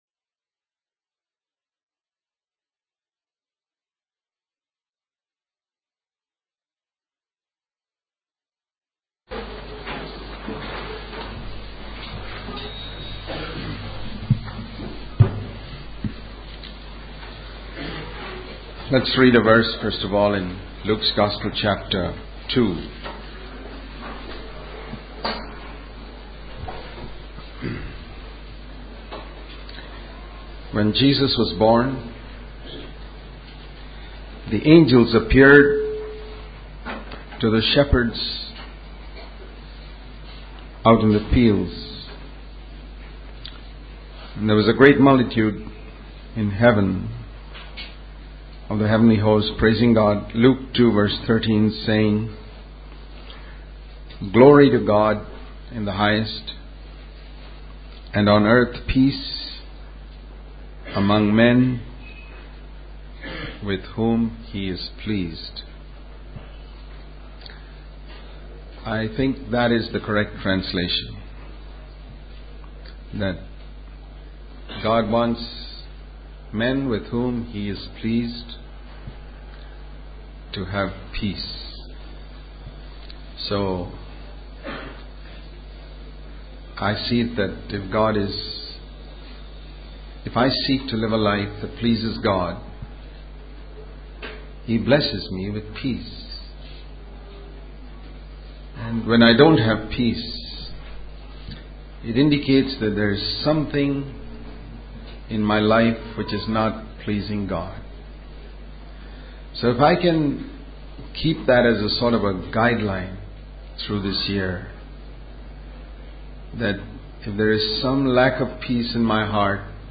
In this sermon, the speaker emphasizes the importance of knowing that God is in control of all things. He shares a story of a boat being lifted by a wave and brought back to shore without damage, highlighting God's protection.